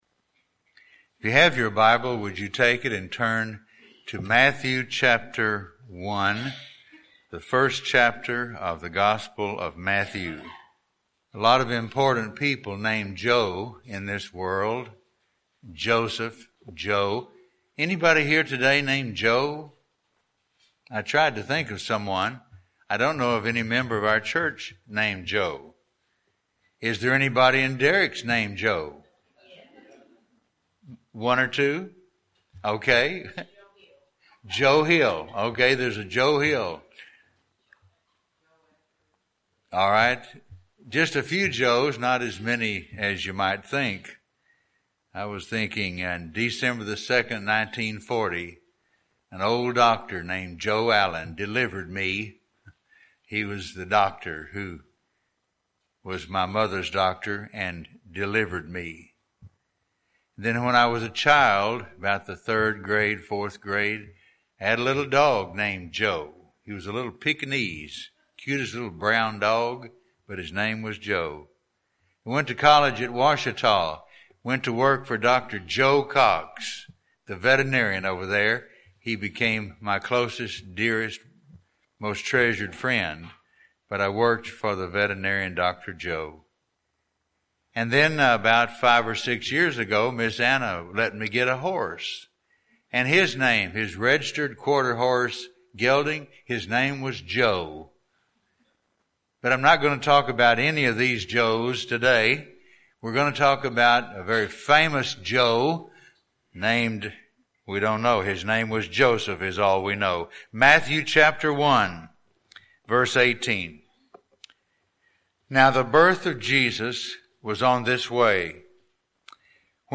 Morning message from Matthew 1:18-25